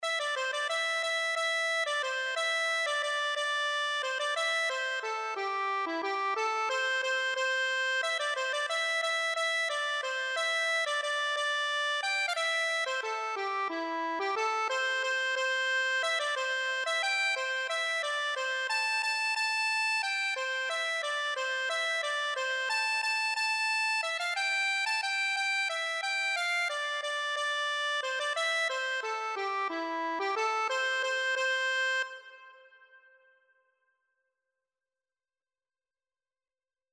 Free Sheet music for Accordion
C major (Sounding Pitch) (View more C major Music for Accordion )
3/4 (View more 3/4 Music)
Accordion  (View more Intermediate Accordion Music)
Traditional (View more Traditional Accordion Music)